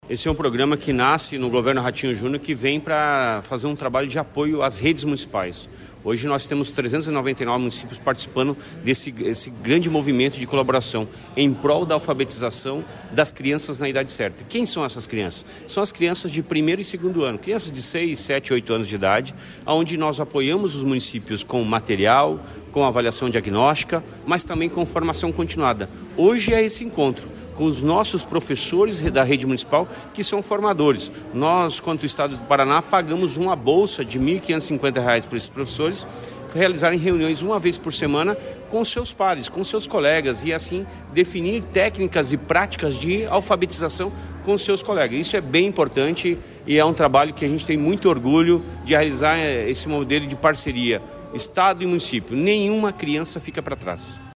Sonora do secretário da Educação, Roni Miranda, sobre o I Congresso dos Formadores em Ação Municípios